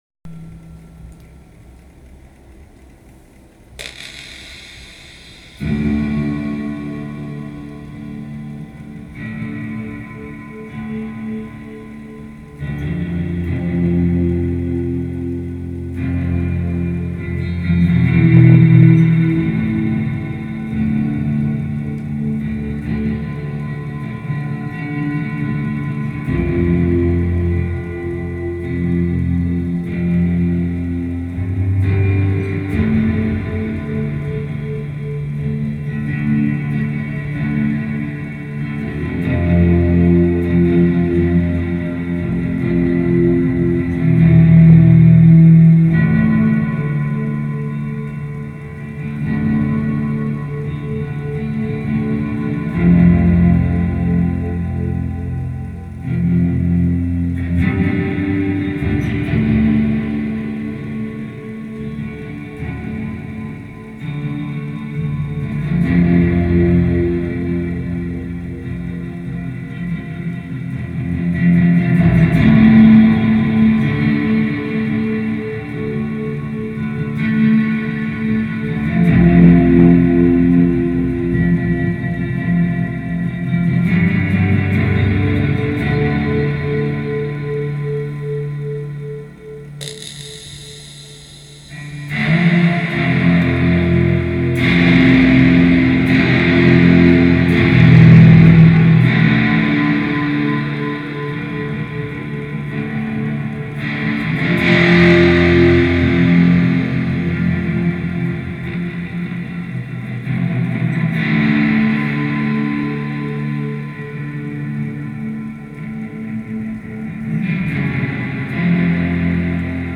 Soundtrack improvised and recorded